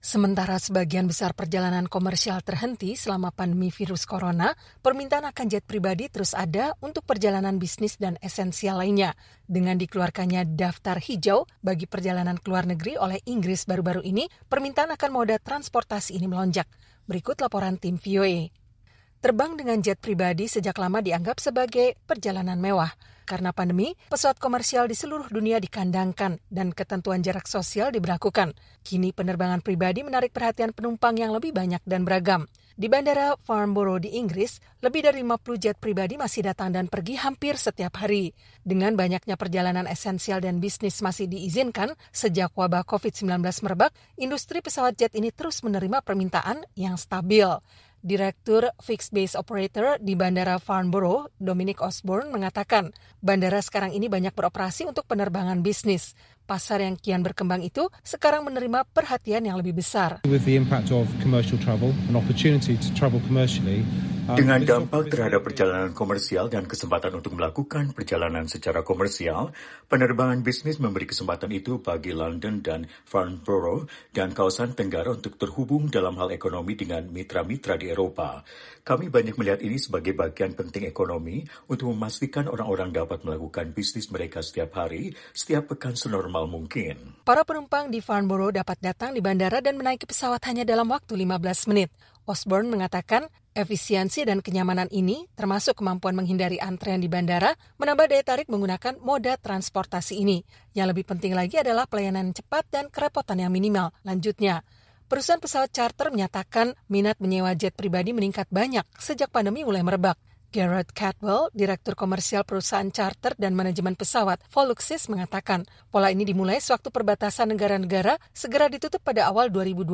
Berikut laporan tim VOA.